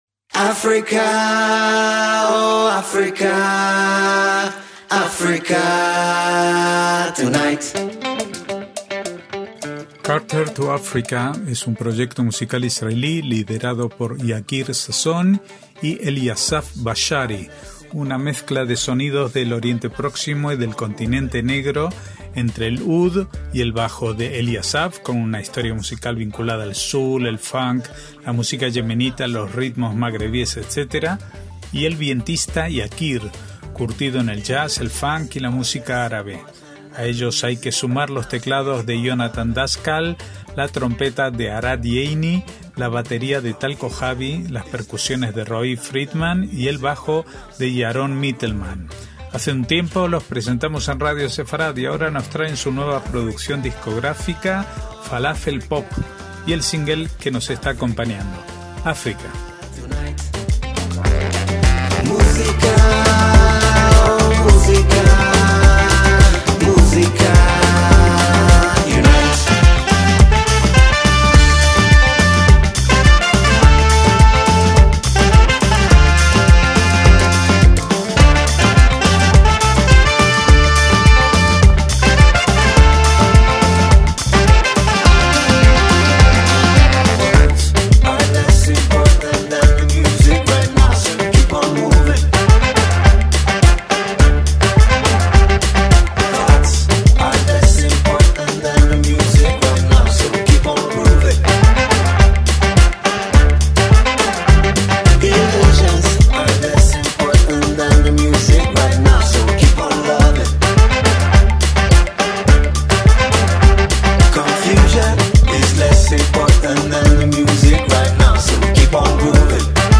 MÚSICA ISRAELÍ
una mezcla de sonidos del Oriente Próximo y del continente negro